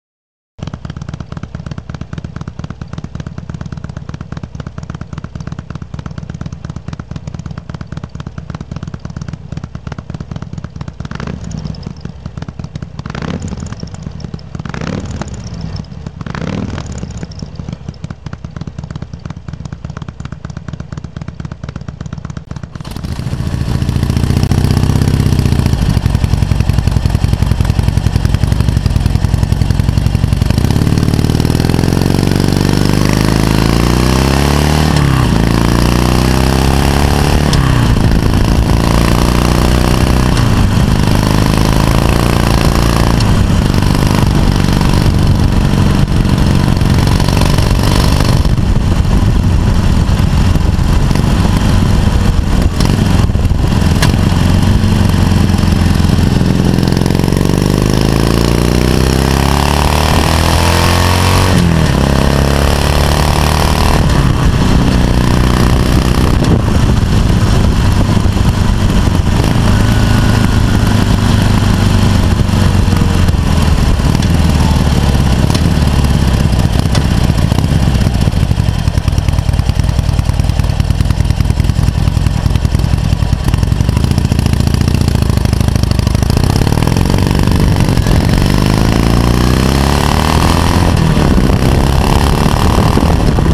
XL Sportster – Échappement stock dépastillé
Sportster-Exhaust_stock_depastille.mp3